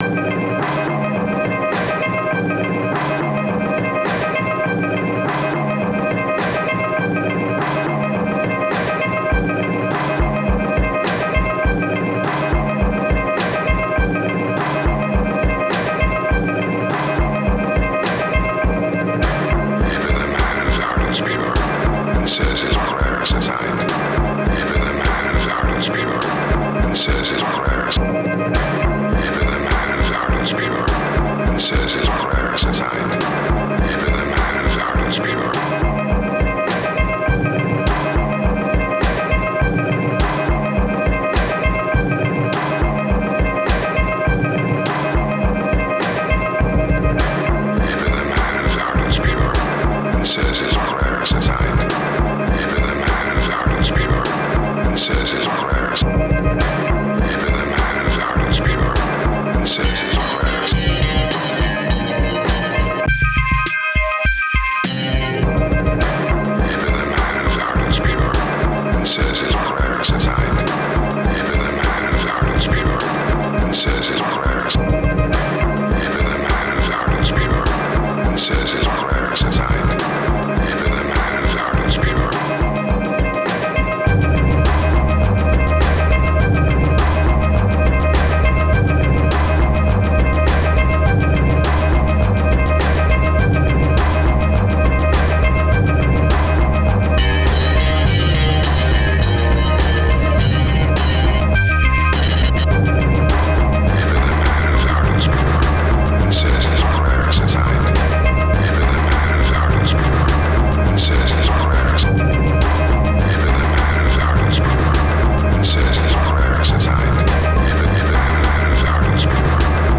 garage rock Halloween riff with a primal beat